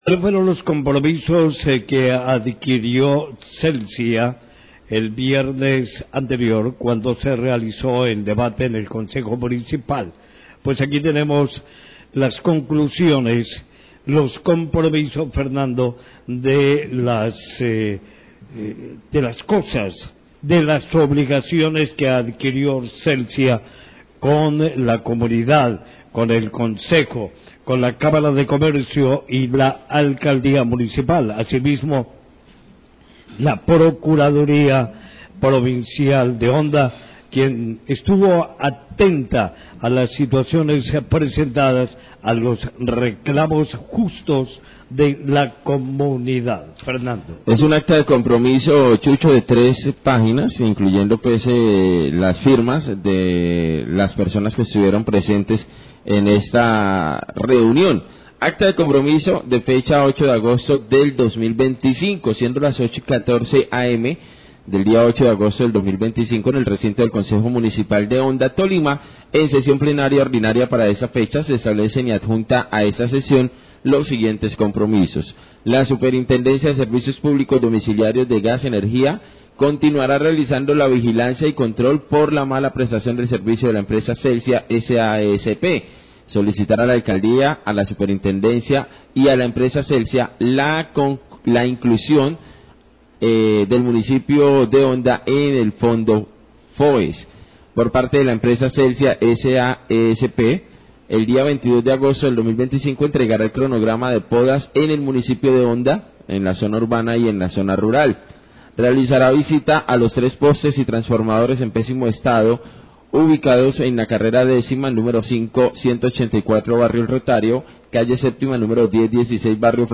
Periodista lee lo acordado en acta de compromiso de la citación de Celsia al Concejo Honda
Radio